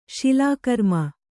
♪ śilākarma